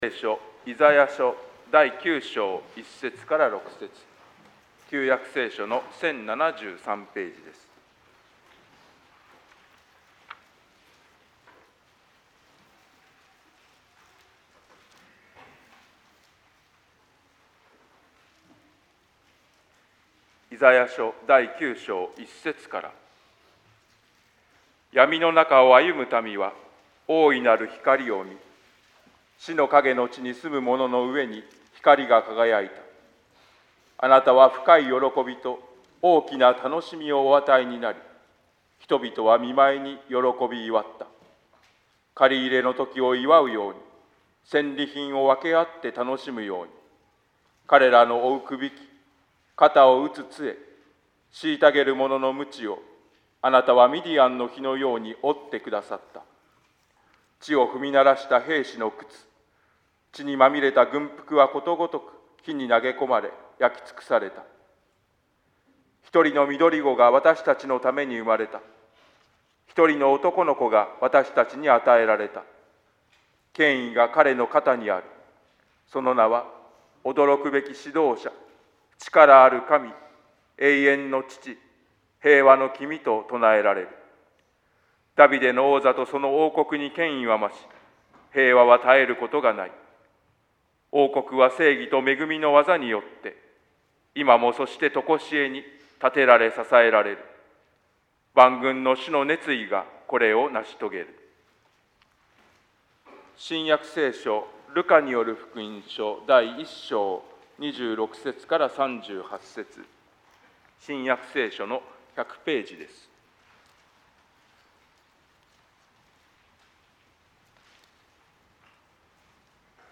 説教「イエスの母となったマリア」